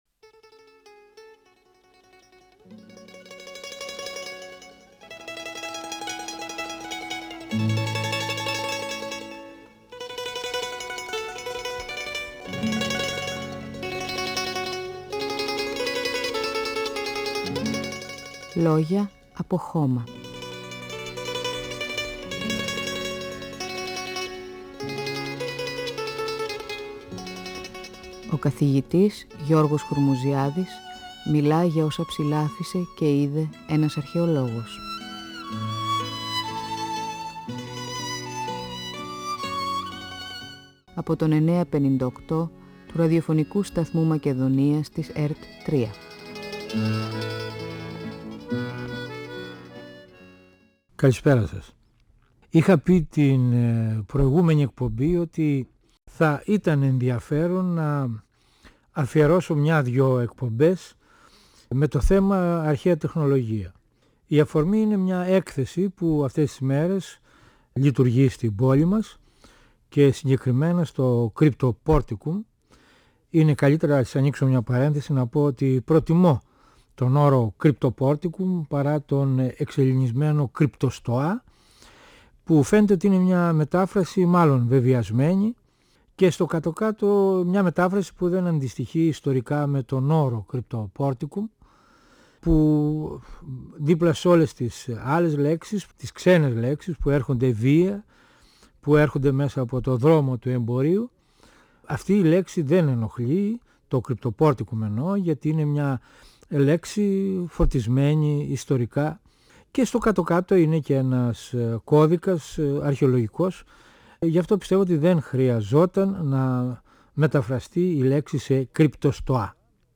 ΦΩΝΕΣ ΑΡΧΕΙΟΥ του 958fm της ΕΡΤ3